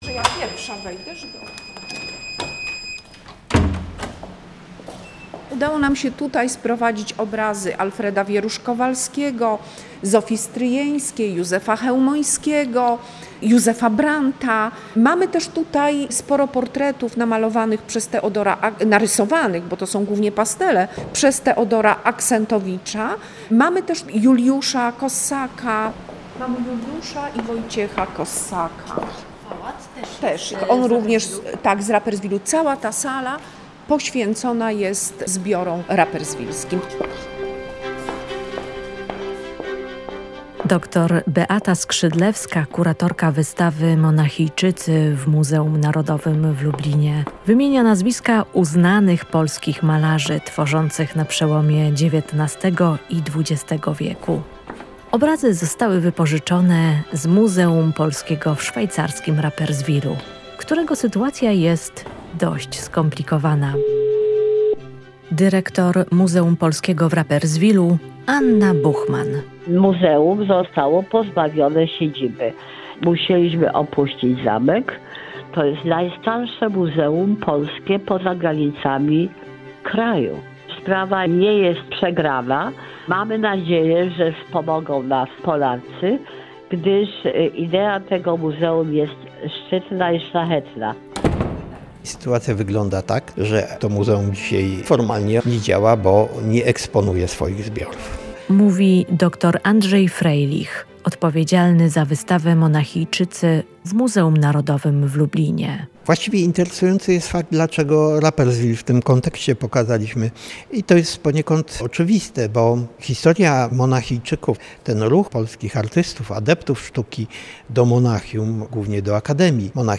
W szwajcarskim Rapperswilu powstało Muzeum Polskie, instytucja wyjątkowa, która przyciągnęła niezwykłe osobowości kolekcjonerów sztuki. O historii muzeum opowiedzą historycy sztuki
Fragmenty tekstów czytają: